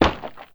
DEMOLISH_Short_01_mono.wav